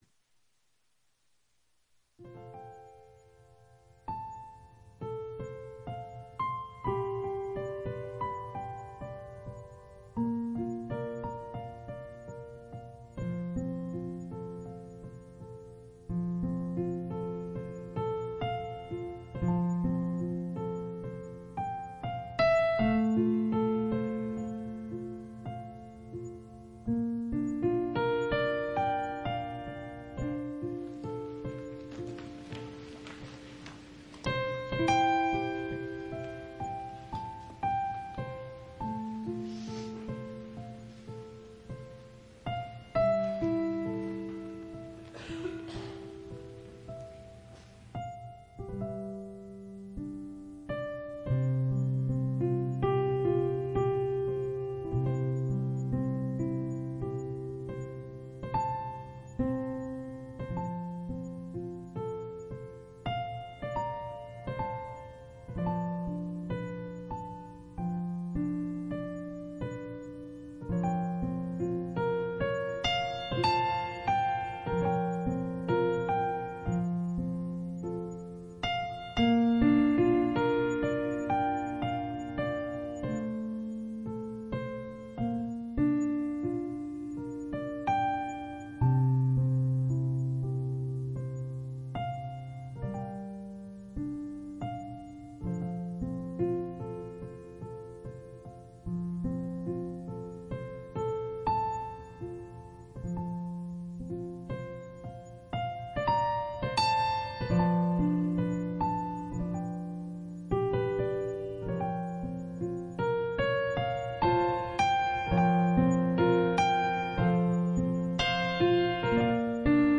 An expository teaching on Numbers chapters 13 and 14. The teaching concludes with an exhortation to Christians to continue to uphold God’s Word, regardless of opposition.